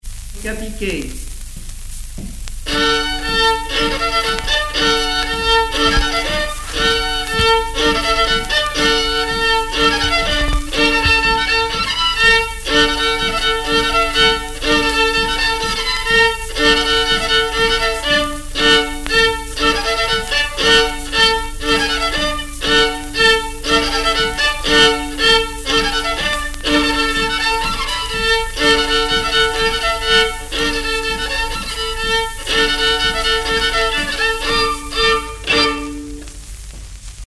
Localisation Saint-Hilaire-le-Vouhis
danse : polka piquée
Pièce musicale inédite